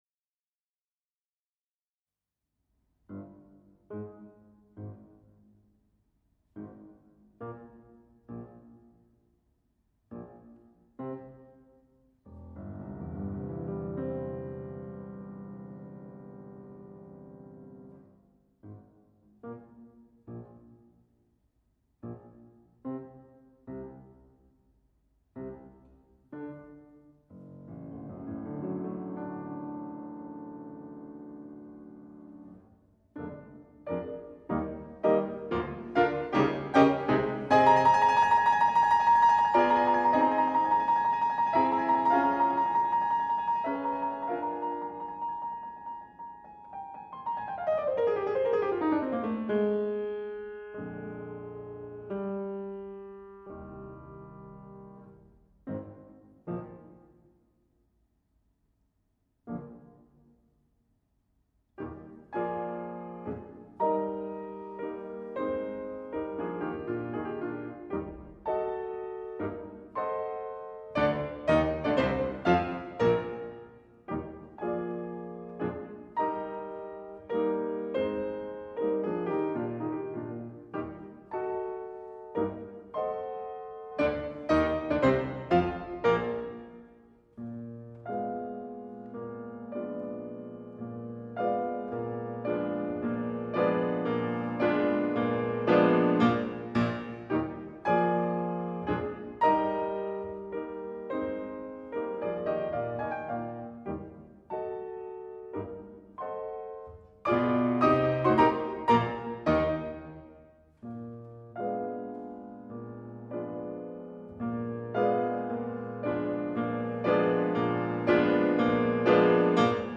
for 2 pianos